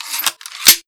RepeatingBow_Draw.wav